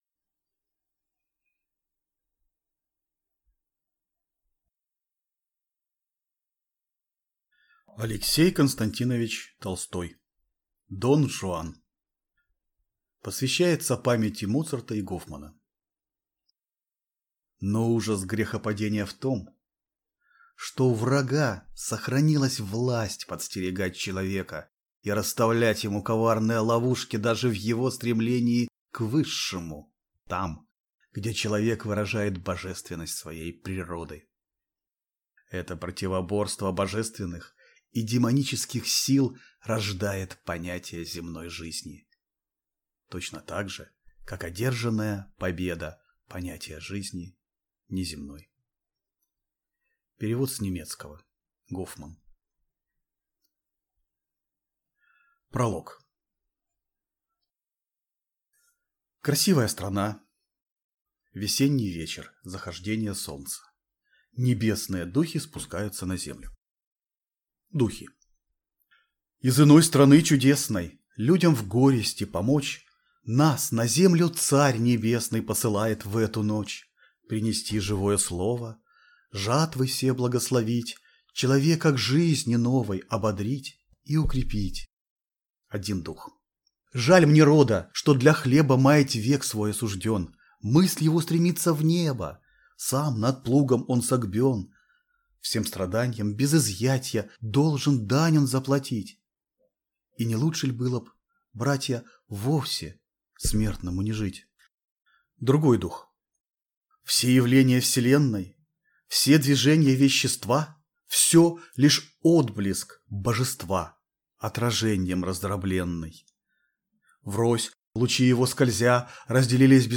Аудиокнига Дон Жуан | Библиотека аудиокниг